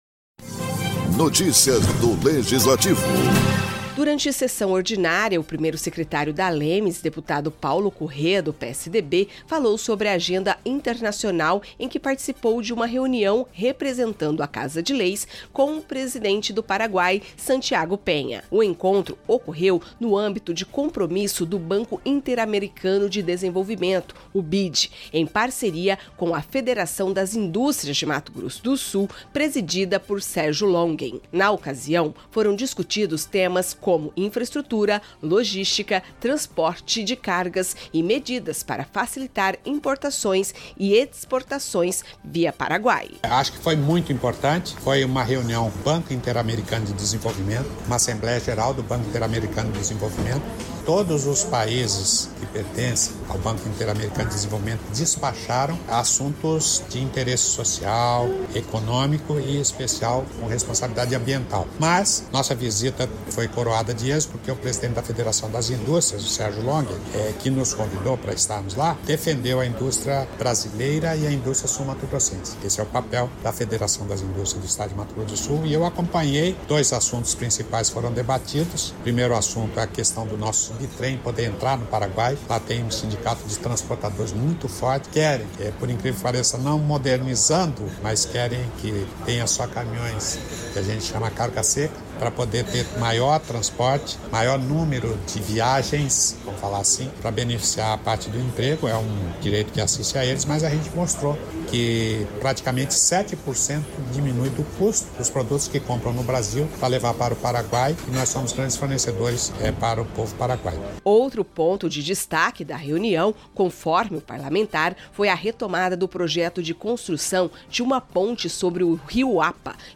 O 1º secretário da ALEMS, deputado Paulo Corrêa (PSDB), falou sobre a agenda internacional em que participou de reunião representando a Casa de Leis, com o presidente do Paraguai, Santiago Peña. Na ocasião, foram discutidos temas como infraestrutura, logística, transporte de cargas e medidas para facilitar importações e exportações via Paraguai.